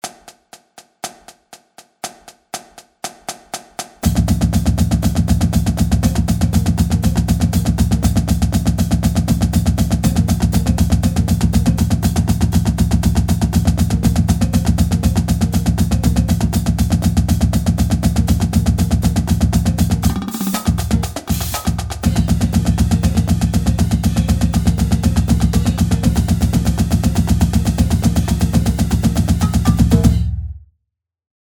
Stick Control 1 - 120.mp3